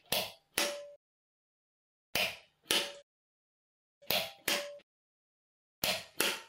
拉动开关
描述：小浴室里拉动线控开关的声音，然后又关闭。
Tag: pullswitch 点击次数 浴室灯 5 amp